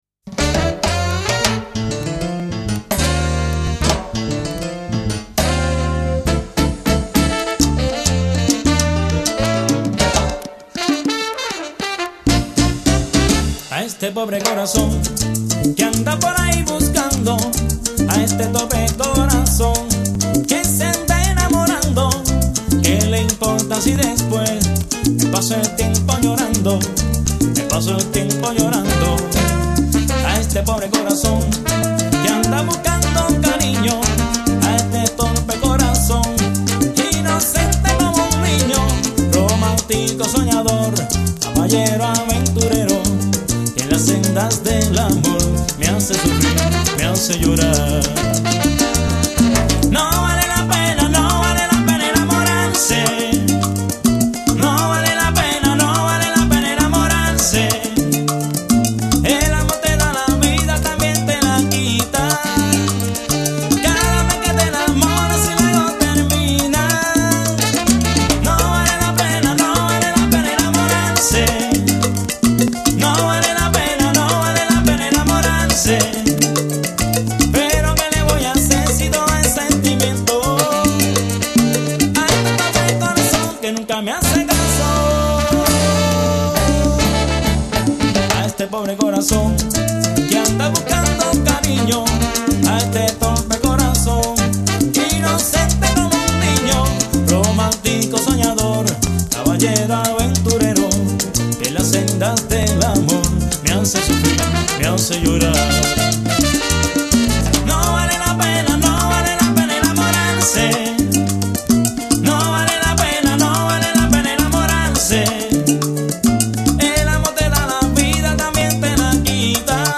замечательной московской группы